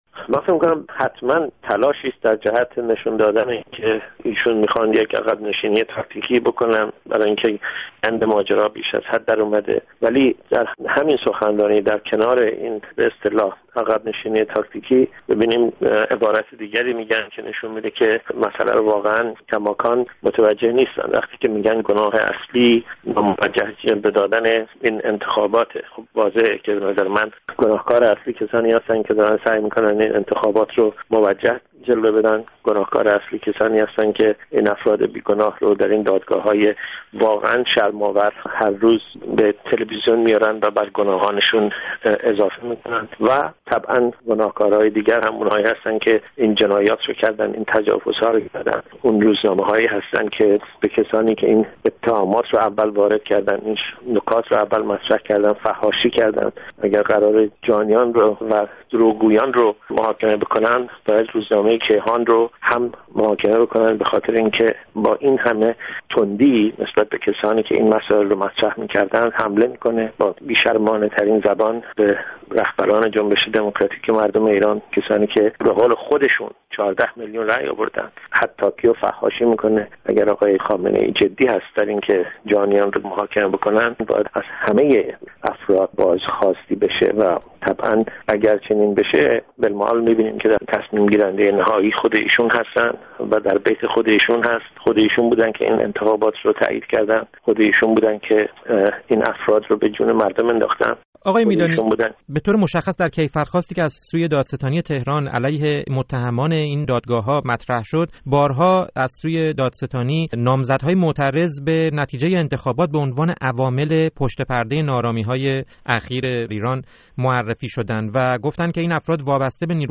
گفت‌وگو با عباس میلانی، نویسنده و رئیس مرکز مطالعات ایران در دانشگاه استنفورد